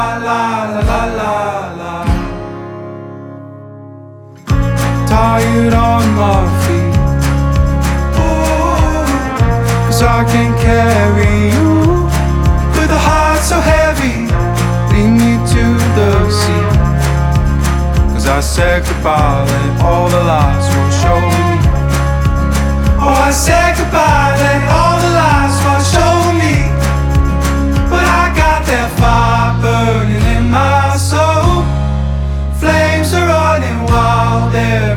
Жанр: Альтернатива / Фолк-рок